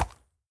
Minecraft Version Minecraft Version snapshot Latest Release | Latest Snapshot snapshot / assets / minecraft / sounds / mob / horse / soft4.ogg Compare With Compare With Latest Release | Latest Snapshot